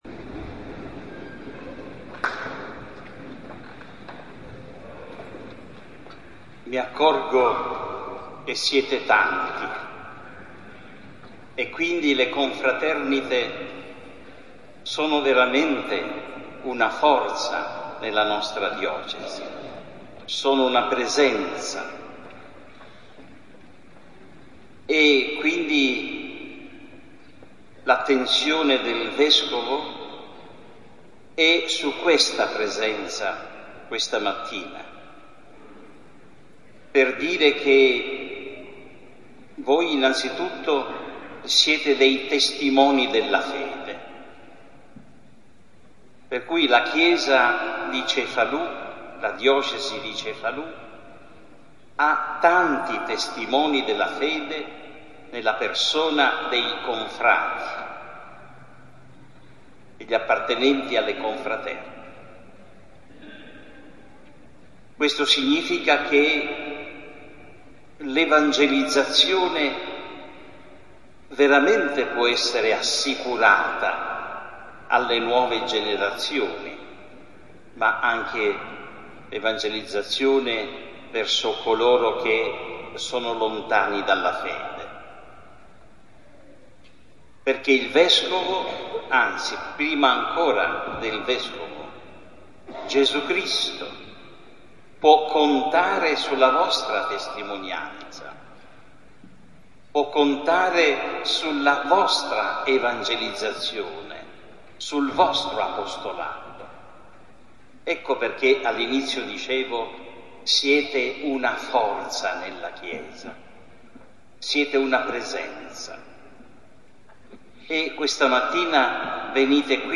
OMELIA DEL VESCOVO GIUSEPPE MARCIANTE durante la S. Messa
05 Omelia Vescovo Marciante.mp3